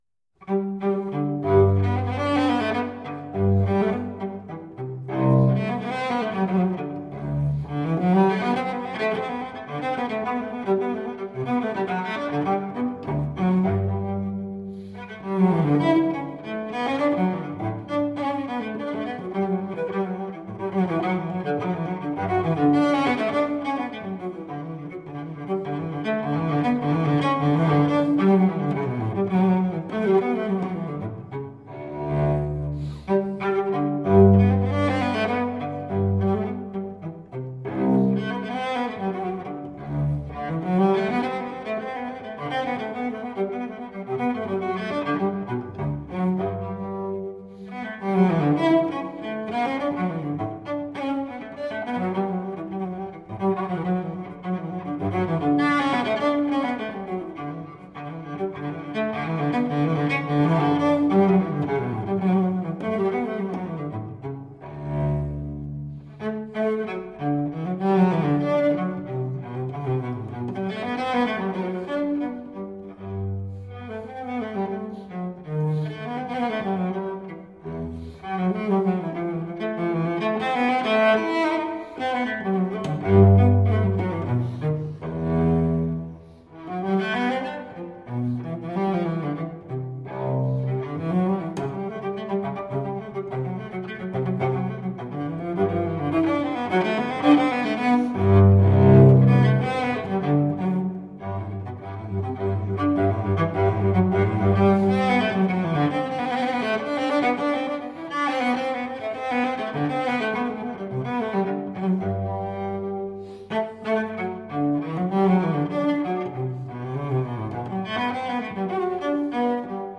Cellist extraordinaire.